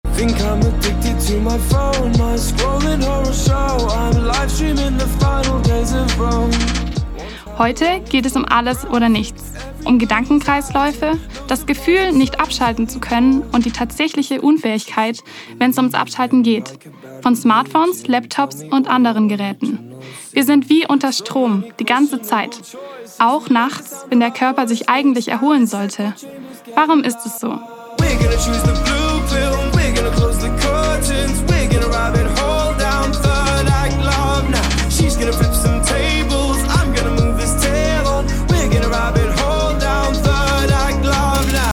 Hörspiele: Die Stromdiebe / Der Schmetterling (529)